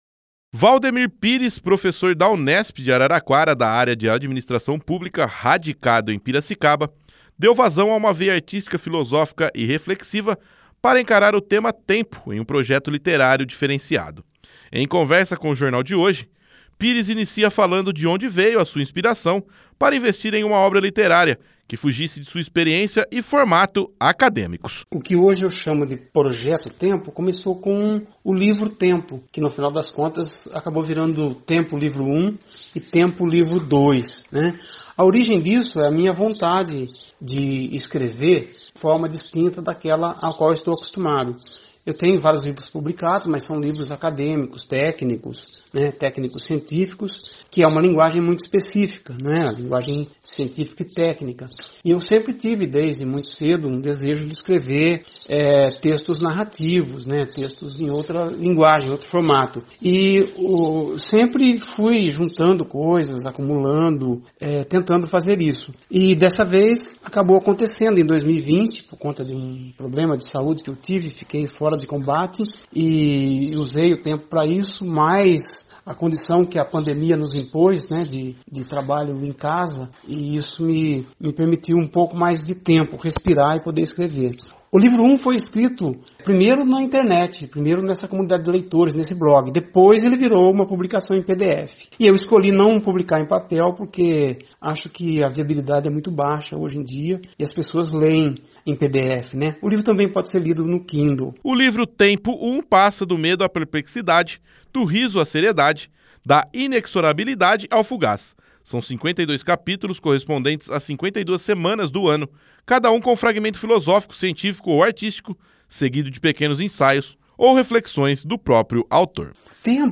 Entrevista na UNIARA FM sobre o projeto "Tempo" e "Tempo